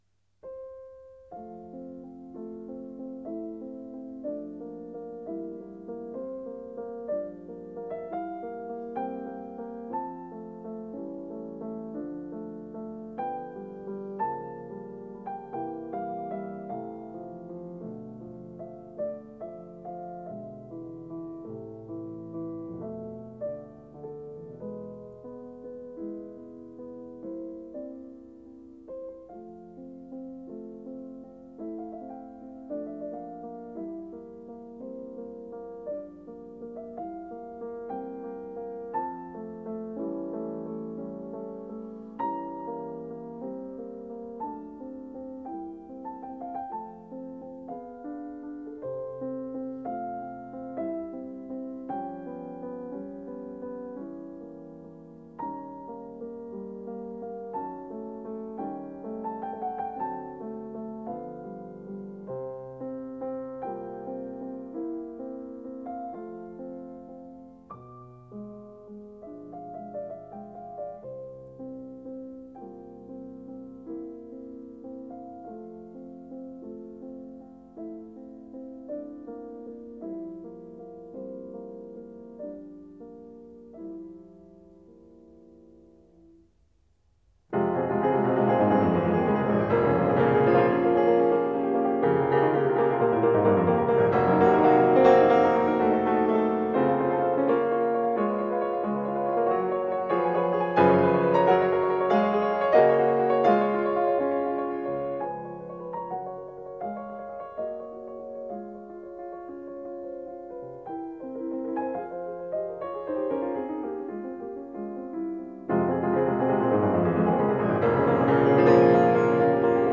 4. F大调，OP15.1，作于1830-1831年，献给费迪南.希勒，如歌的行板。